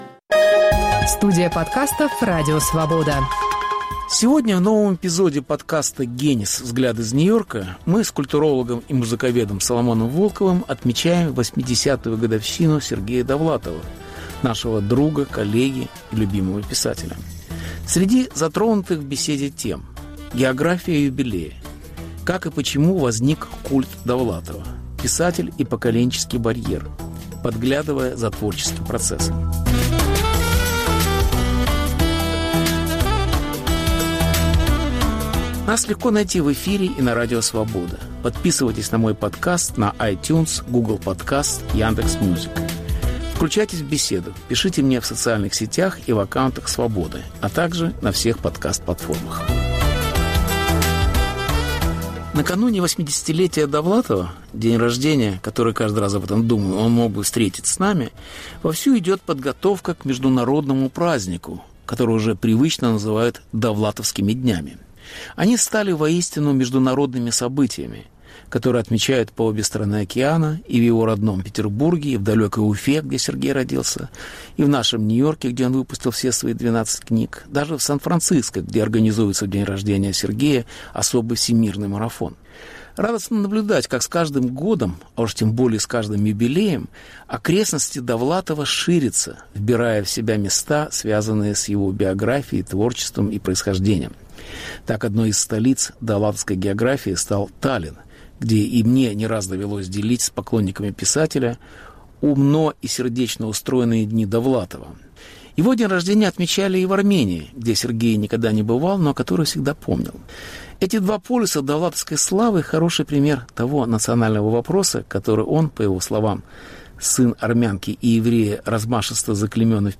Как отмечают 80-летие писателя. Беседа с Соломоном Волковым